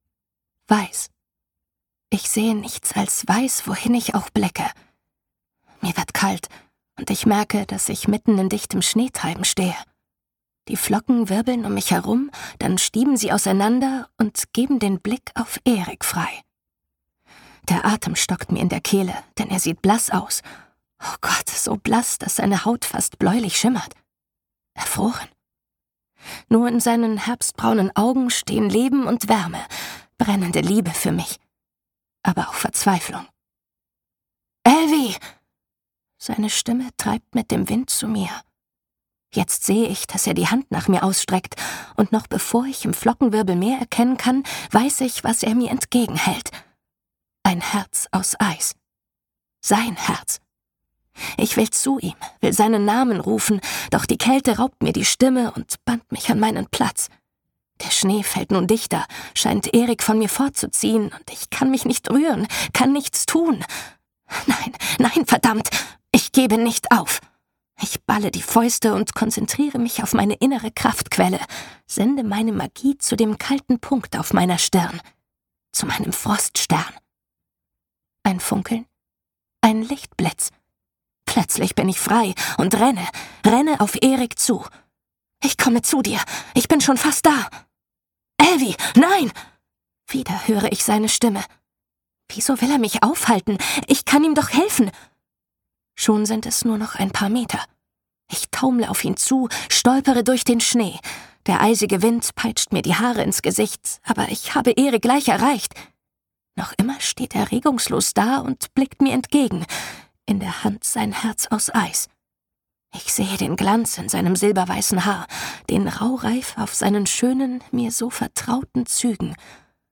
gibt der mutigen Elvy ihre frische Stimme, zieht temporeich in die Abenteuerszenen und verweilt sehnsuchtsvoll im romantischen Augenblick.
tritt als Erik in Erscheinung und verleiht ihm Sinnlichkeit und Anziehungskraft.